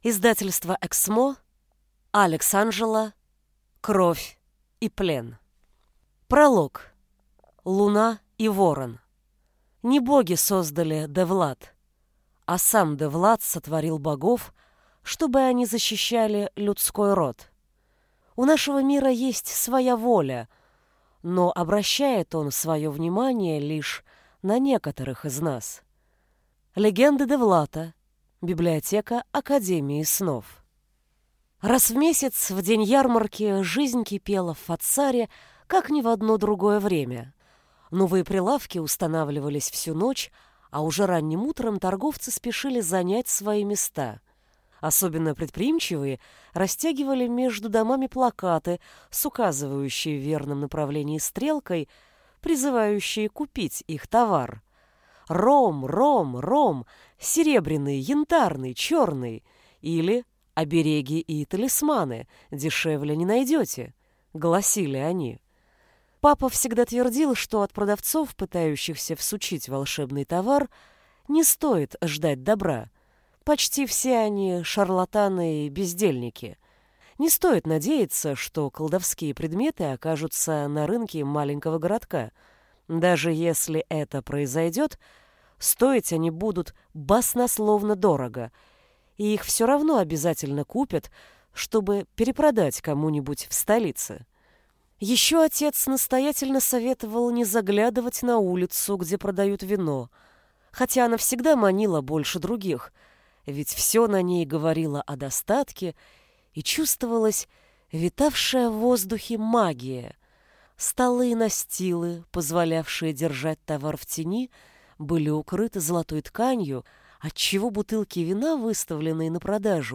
Аудиокнига Кровь и Плен | Библиотека аудиокниг